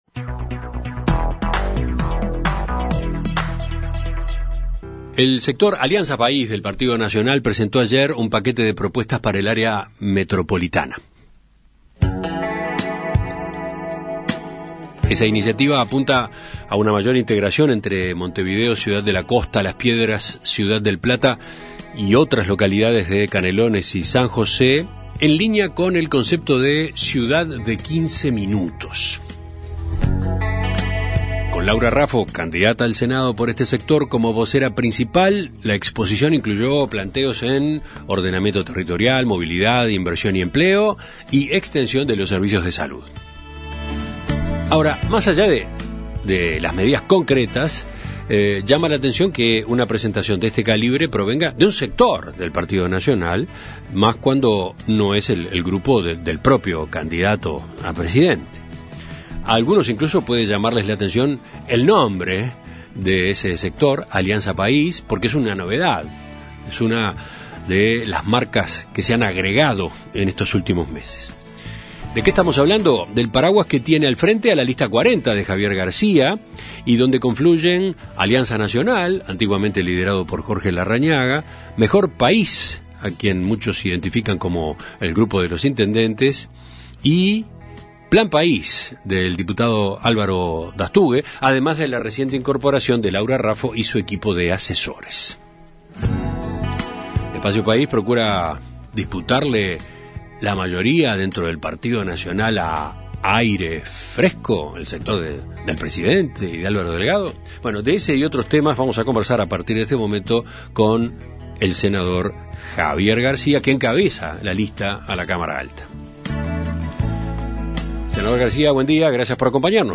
En Perspectiva Zona 1 – Entrevista Central: Javier García - Océano